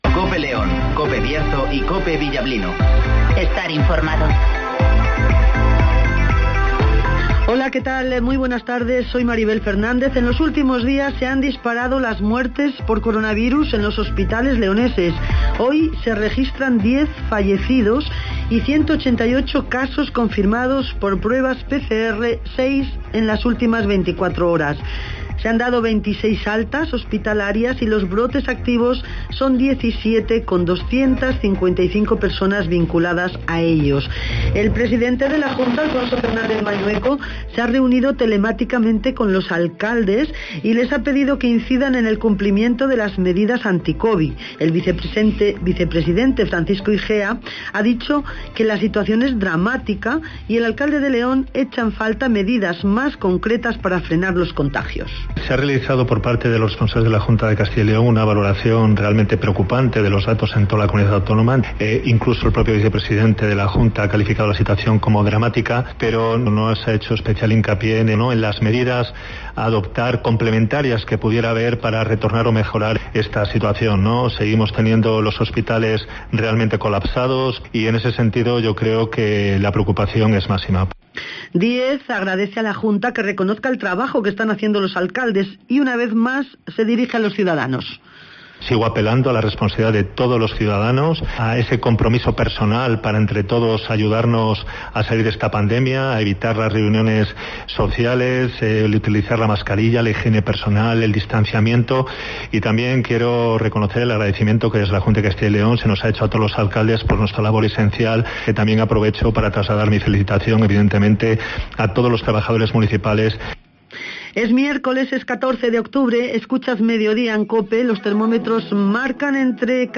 INFORMATIVOS
Conocemos las noticias de las últimas horas del Bierzo y León, con las voces de los protagonistas.